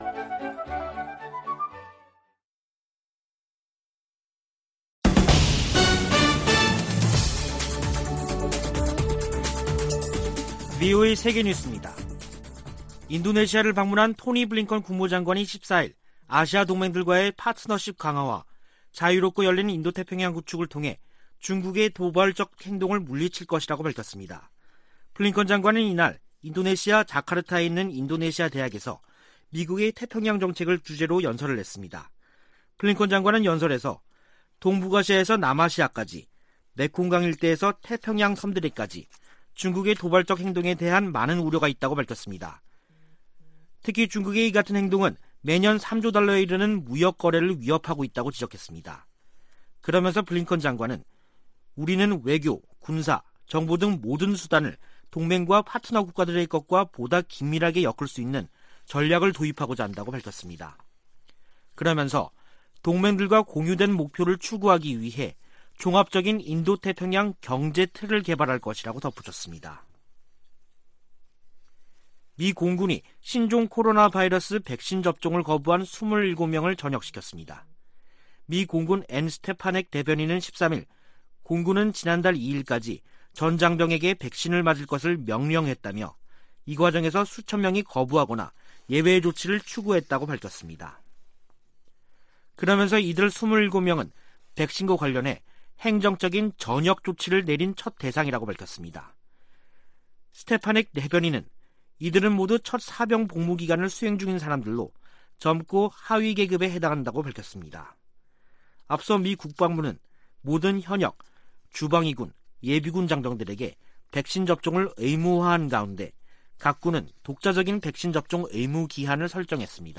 VOA 한국어 간판 뉴스 프로그램 '뉴스 투데이', 2021년 12월 14일 2부 방송입니다. 미 국무부는 베이징 동계올림픽 외교적 보이콧에 관해 ‘한국 스스로 결정할 일’이라고 밝혔습니다. 미 국방부는 한국군 전시작전통제권 전환을 위한 완전운용능력(FOC) 평가를 내년 여름에 실시하는 계획을 재확인했습니다. 미 재무부가 북한 내 인권 유린에 연루된 개인과 기관을 제재한 효과가 제한적일 것이라고 전문가들은 평가했습니다.